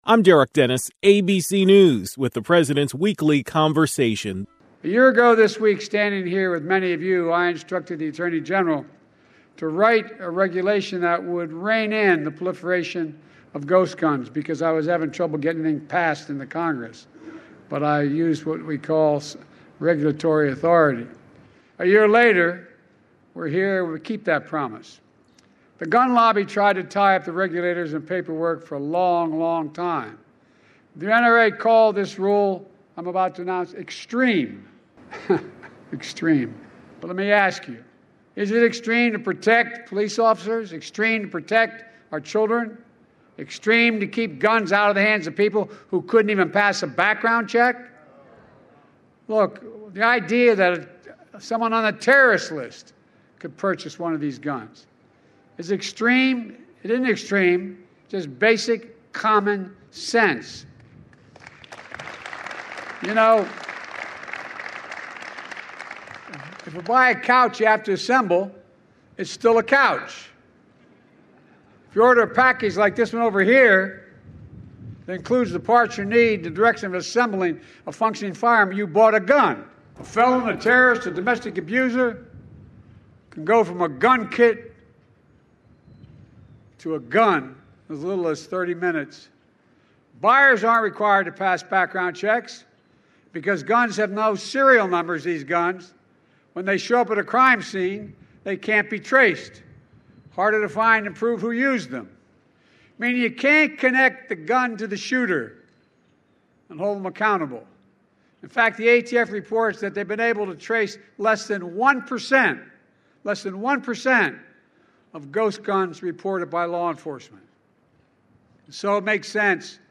President Biden delivered a speech announcing his actions to fight gun crimes.